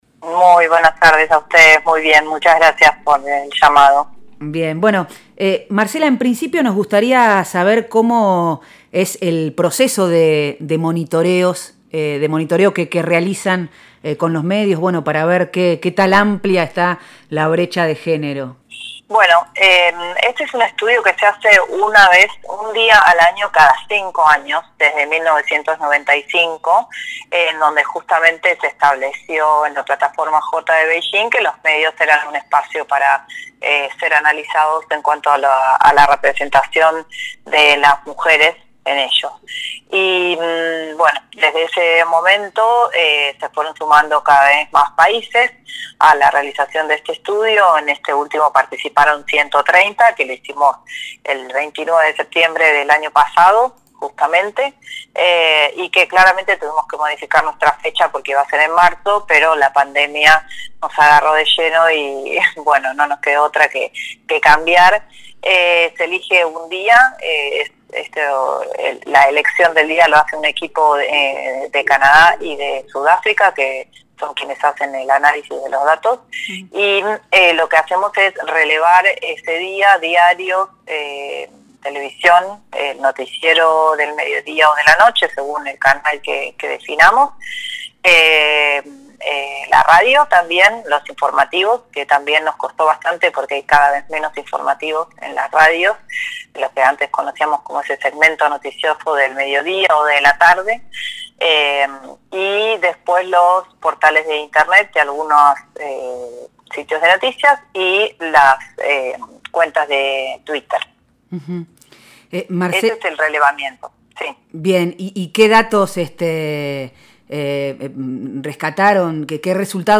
En diálogo con Nosotres les Otres habló sobre cómo es el proceso de observación, qué datos obtuvieron el último año a nivel mundial y en Argentina en particular.